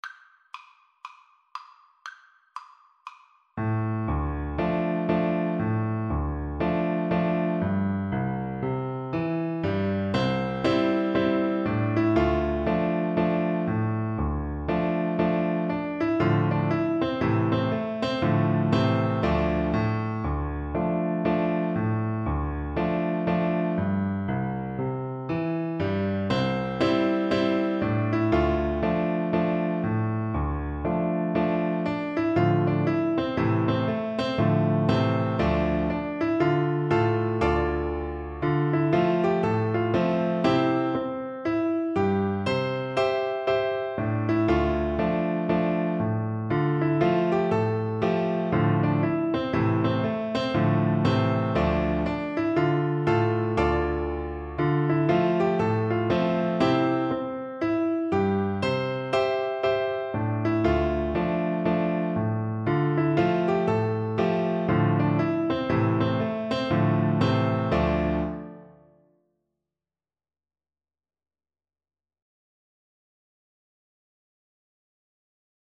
Cello
Traditional Music of unknown author.
4/4 (View more 4/4 Music)
C major (Sounding Pitch) (View more C major Music for Cello )
Presto =c.180 (View more music marked Presto)